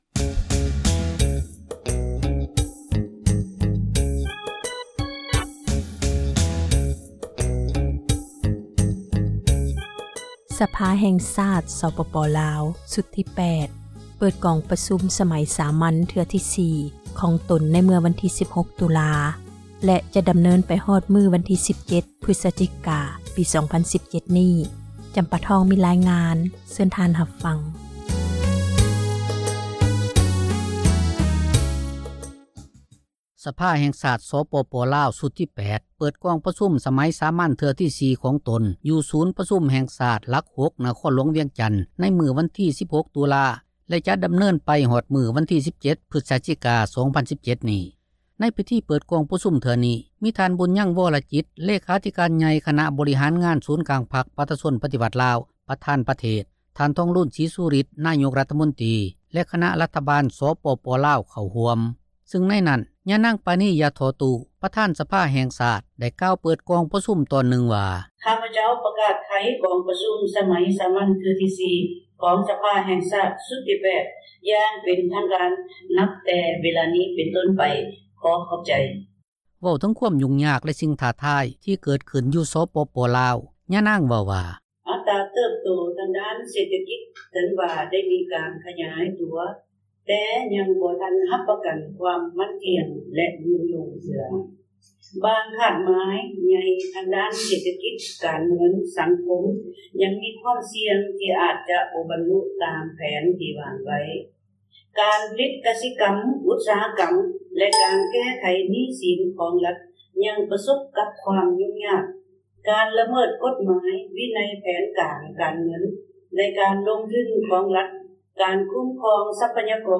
ກອງປະຊຸມສະພາແຫ່ງຊາດ ເທື່ອທີ 4 – ຂ່າວລາວ ວິທຍຸເອເຊັຽເສຣີ ພາສາລາວ
ຍານາງ ປານີ ຢາທໍ່ຕູ້ ປະທານ ສະພາແຫ່ງຊາດ ໄດ້ກ່າວເປີດກອງປະຊຸມ ຕອນນຶ່ງວ່າ: